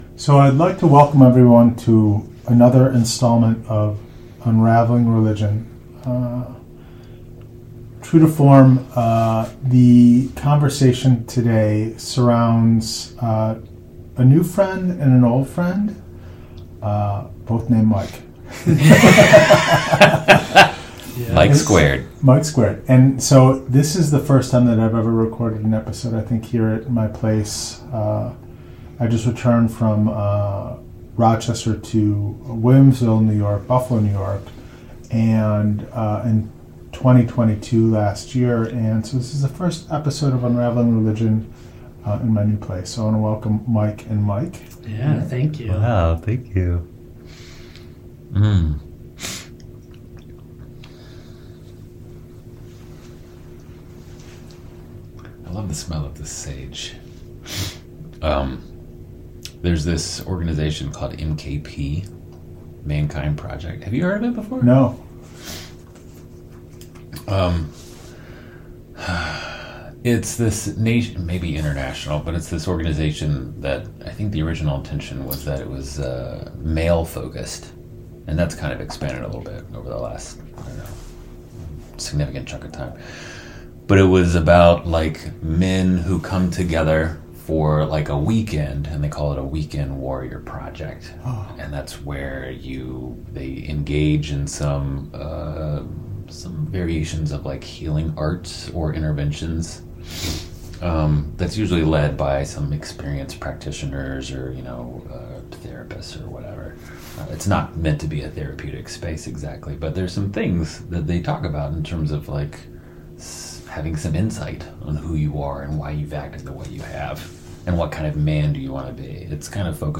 Part 1 Three Words On Your Tombstone; Three Counselors Talk On Death, Meditation, Meaning, and Suffering